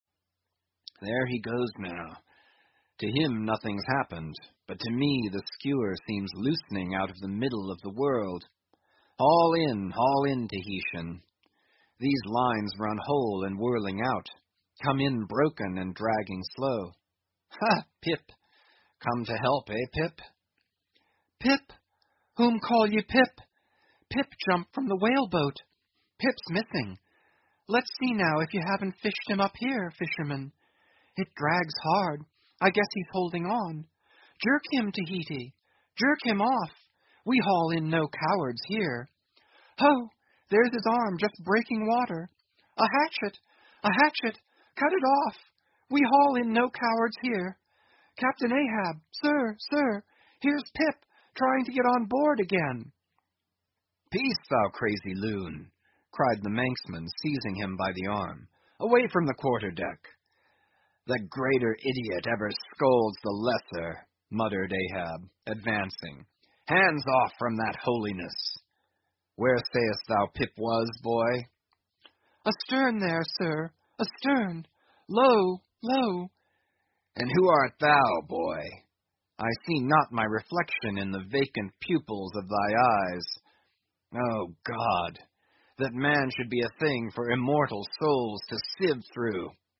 英语听书《白鲸记》第966期 听力文件下载—在线英语听力室